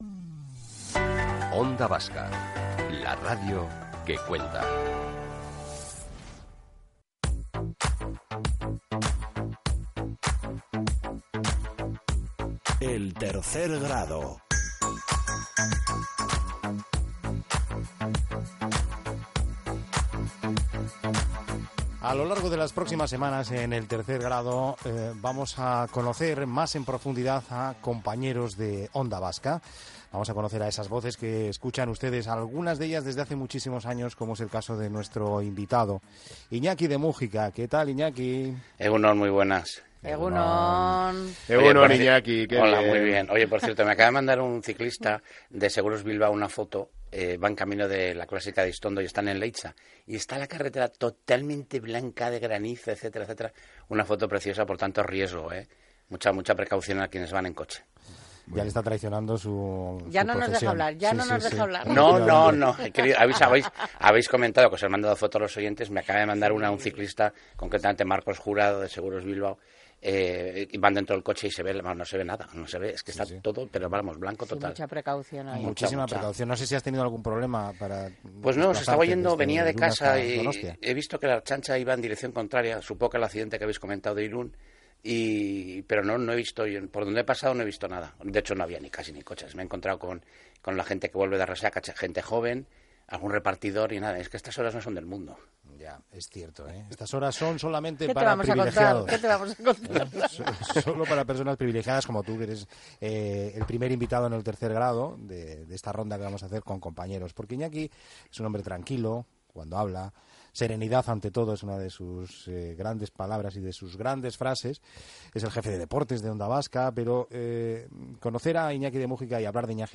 en los estudios de Onda Vasca
Vuelve a disfrutar de esta gran entrevista y descubre qué es lo que pensaban sus compañeros de él.